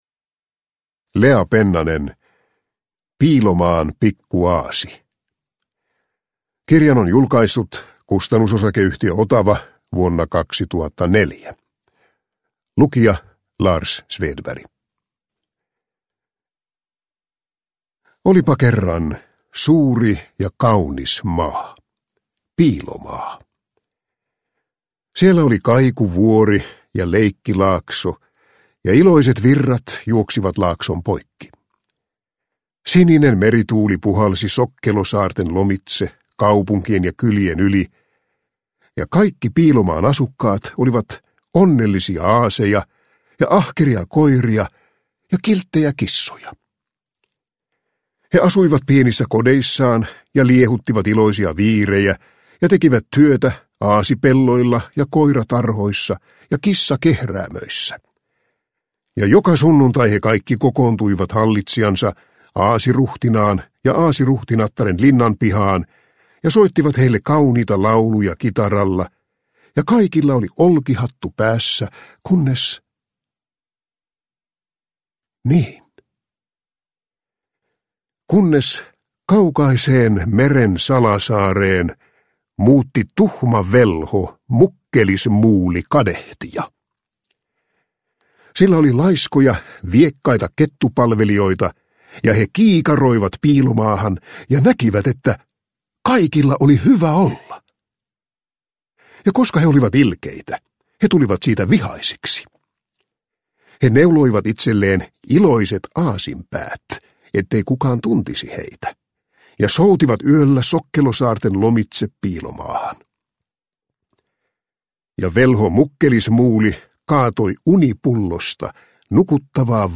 Piilomaan pikku aasi – Ljudbok – Laddas ner